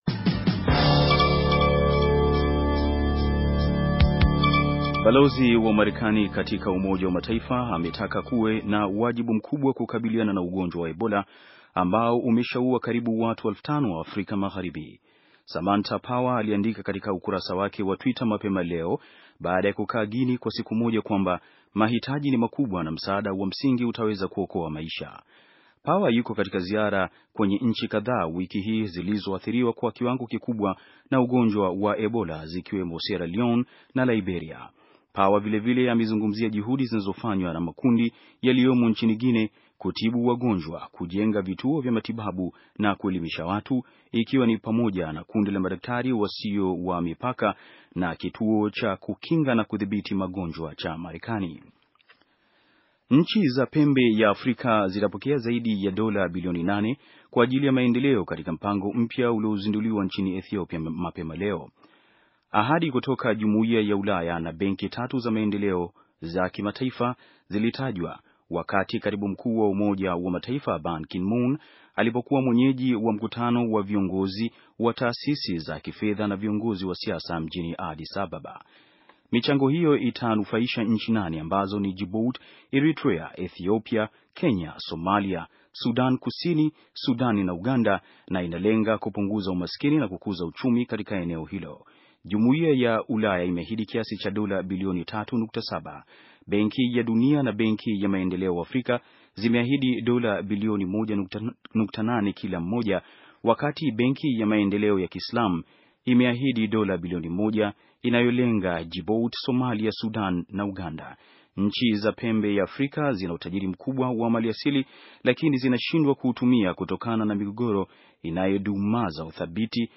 Taarifa ya habari - 5:18